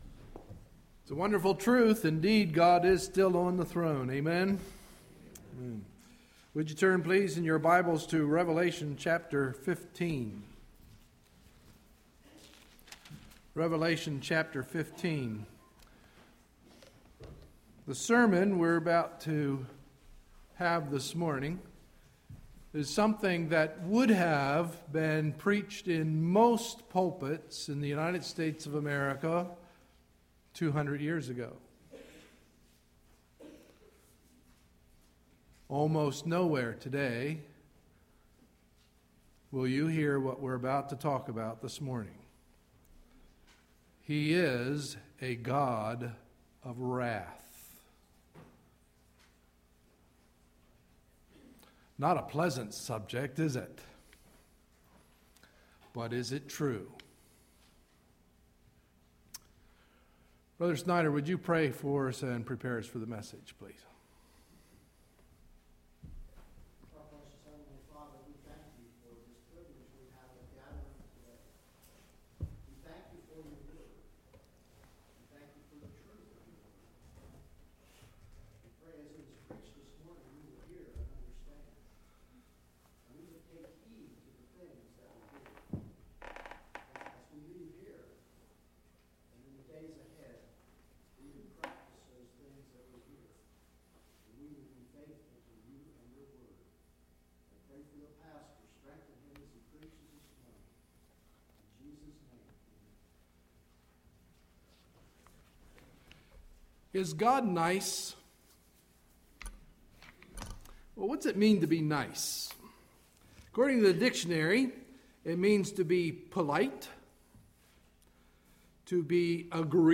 Sunday, October 16, 2011 – Morning Message